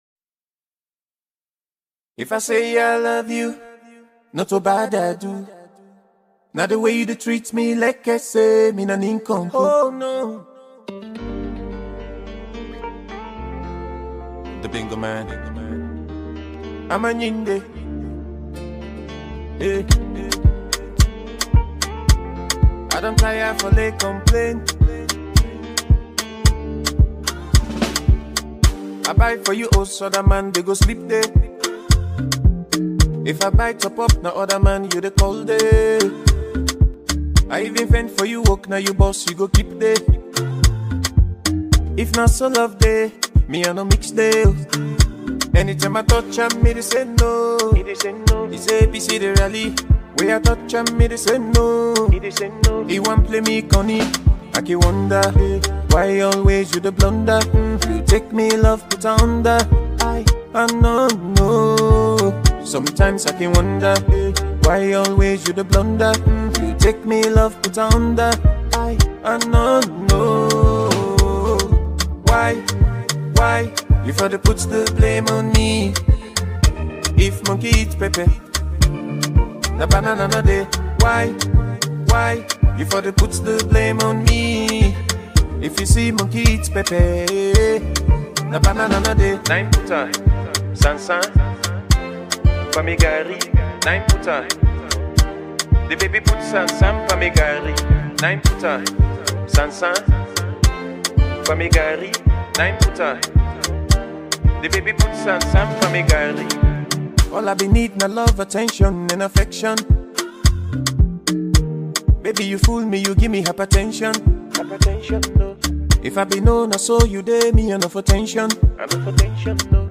very soothing melodies
melodious love song